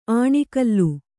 ♪ āṇikallu